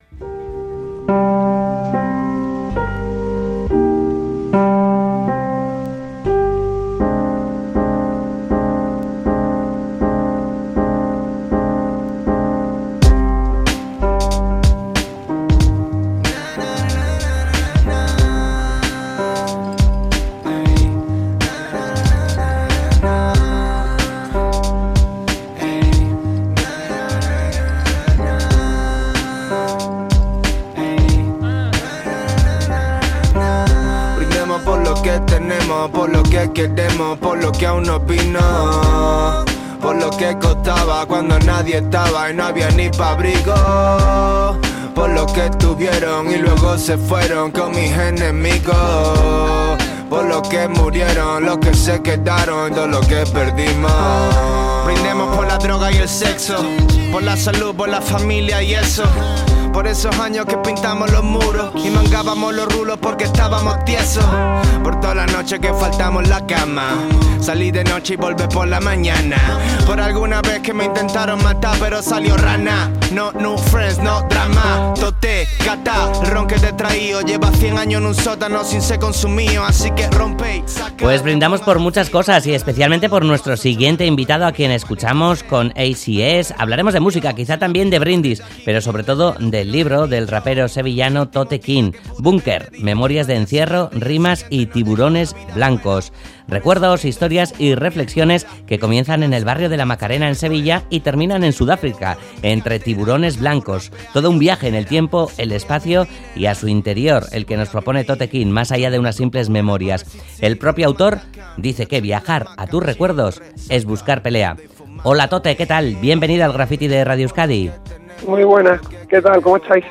Audio: Hablamos con el rapero sevillano Toteking sobre su primer libro, "Búnker", en el que se mezclan música, recuerdos, historias y reflexiones del autor.